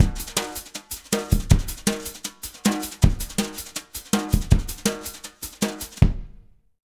Index of /90_sSampleCDs/USB Soundscan vol.08 - Jazz Latin Drumloops [AKAI] 1CD/Partition A/06-160JUNGLB
160JUNGLE6-L.wav